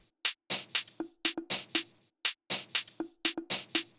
Drumloop 120bpm 08-B.wav